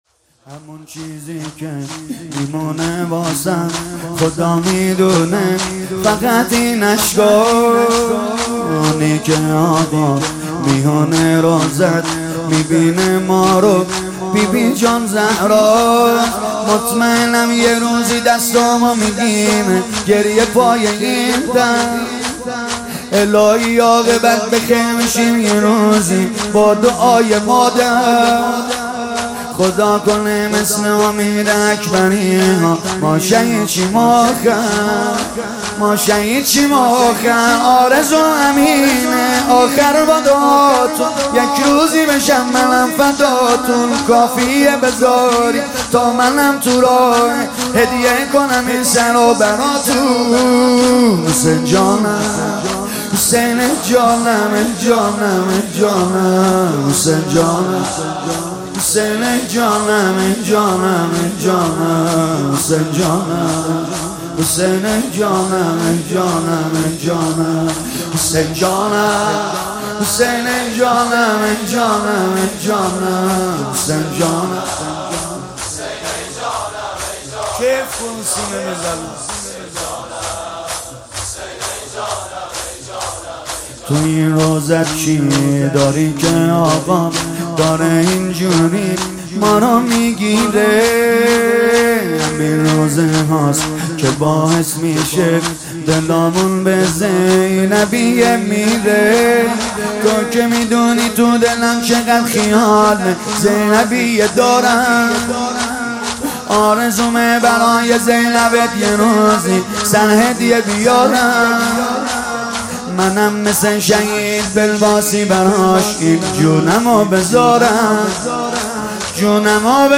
هیات فداییان حسین (ع) اصفهان